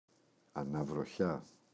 αναβροχιά, η [anavroꞋça]